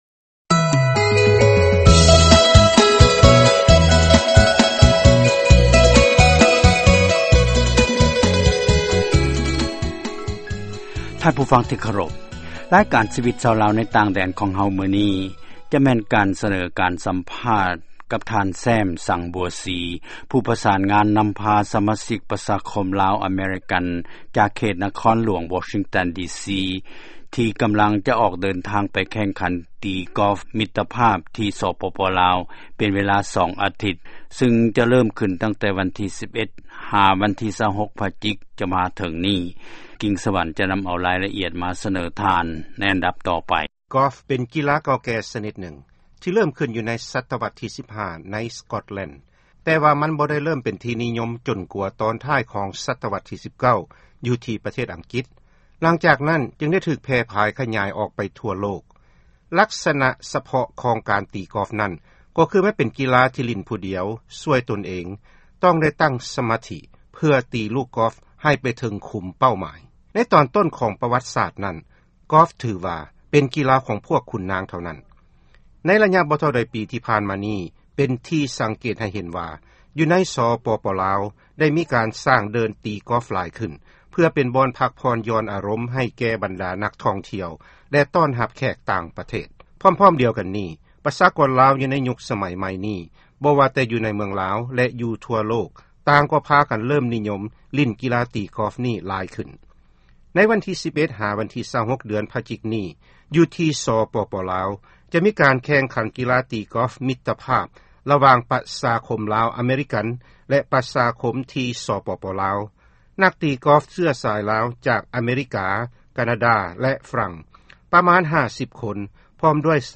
ຟັງຂ່າວ ການສໍາພາດ ນັກຕີກິອຟ